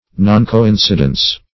Noncoincidence \Non`co*in"ci*dence\, n.
noncoincidence.mp3